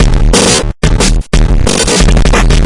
变异的180bpm " Muta
描述：错综复杂的鼓声是用Fruitityloops制作的，用许多插件进行了大量的处理。
Tag: 畸变 循环 毛刺